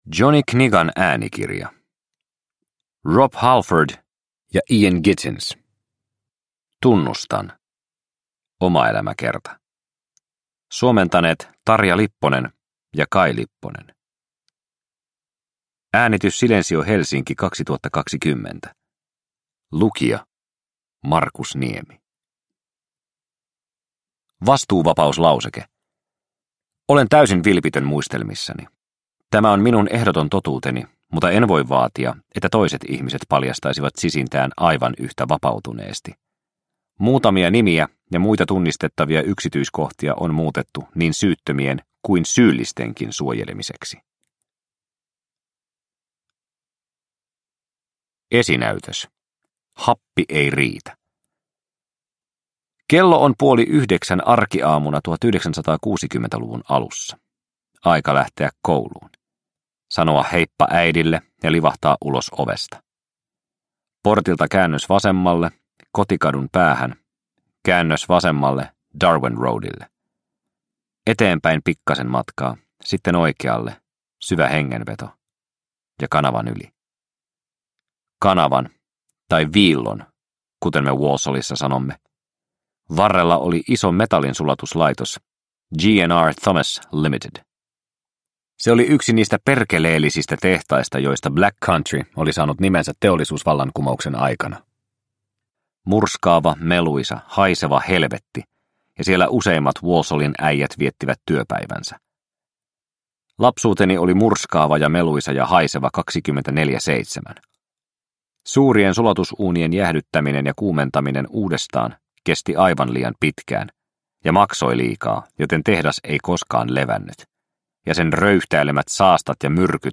Tunnustan – Ljudbok – Laddas ner